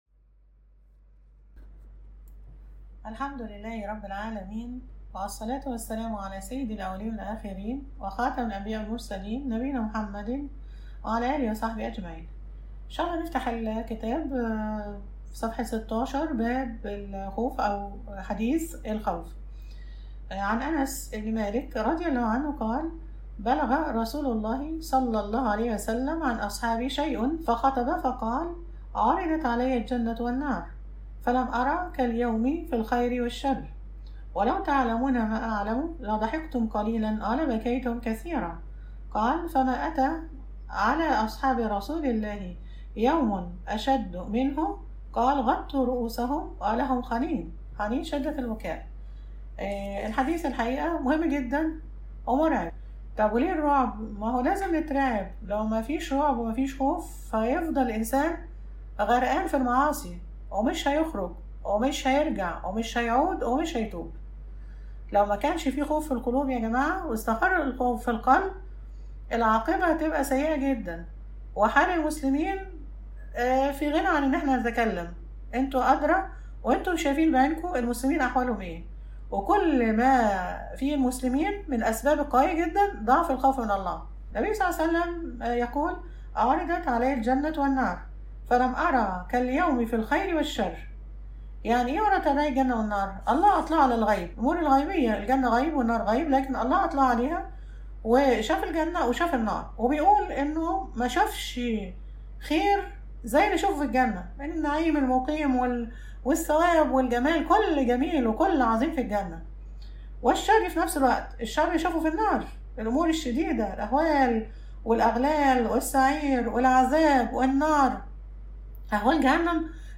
المحاضرة الثالثة_”الخوف من الله”